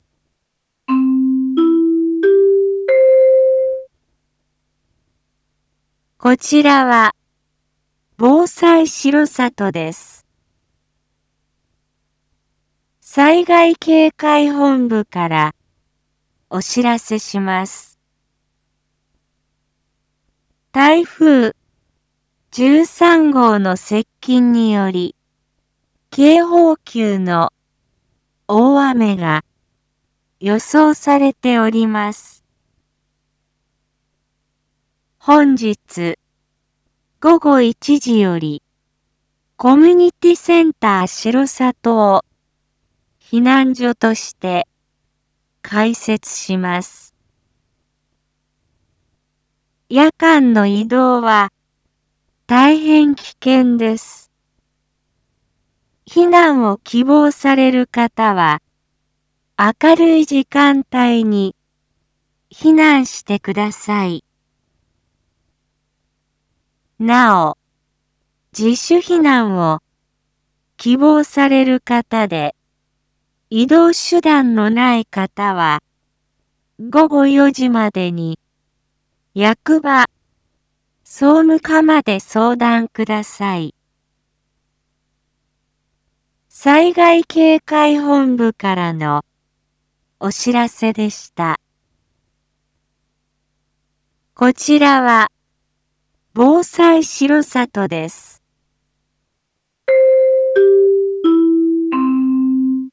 Back Home 一般放送情報 音声放送 再生 一般放送情報 登録日時：2023-09-08 11:01:44 タイトル：台風１３号に伴う避難所の開設について インフォメーション：こちらは、防災しろさとです。